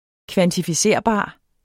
Udtale [ kvantifiˈseɐ̯ˀˌbɑˀ ]